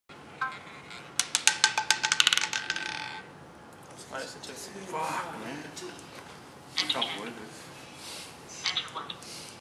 Have you heard the ball he is using during his conference?.
No bounce at all, as if it was a lead ball.